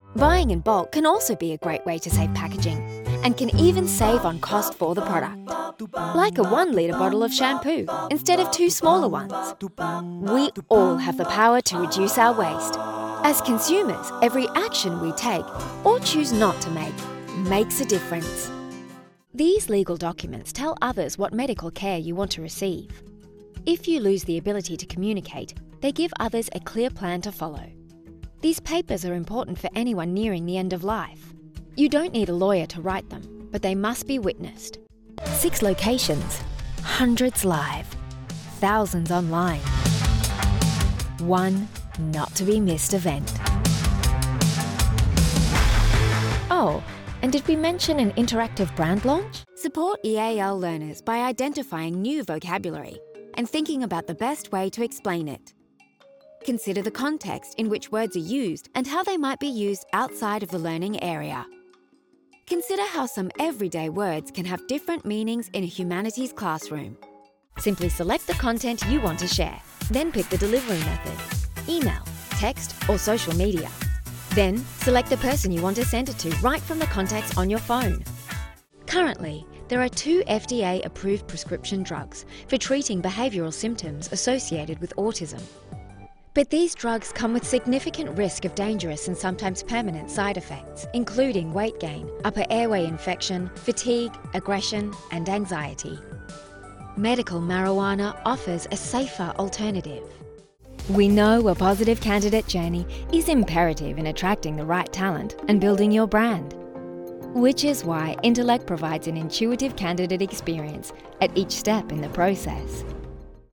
Female
English (Australian)
Explainer Videos
A Few Explainer Projects
Words that describe my voice are Natural, Explainer, Conversational.